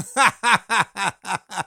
male_laugh3.ogg